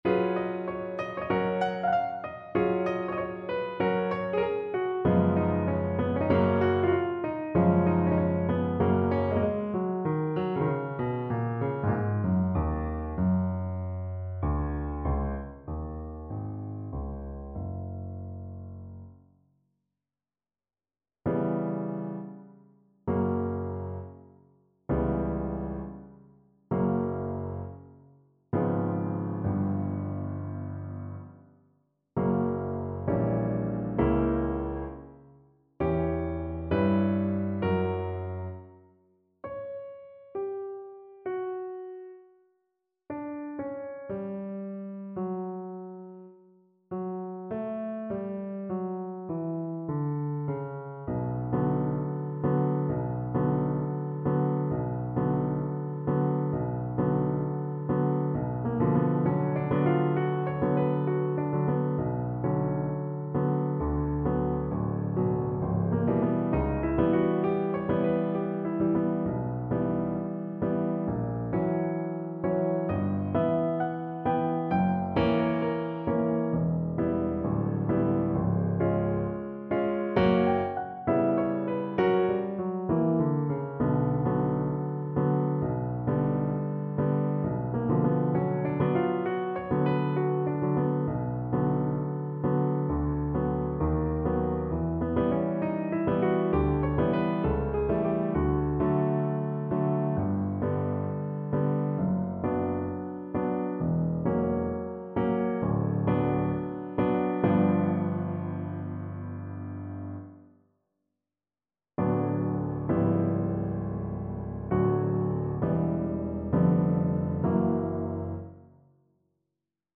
Classical Tchaikovsky, Pyotr Ilyich Lensky's Aria from Eugene Onegin Viola version
Play (or use space bar on your keyboard) Pause Music Playalong - Piano Accompaniment Playalong Band Accompaniment not yet available transpose reset tempo print settings full screen
Viola
4/4 (View more 4/4 Music)
D major (Sounding Pitch) (View more D major Music for Viola )
Moderato =96
Classical (View more Classical Viola Music)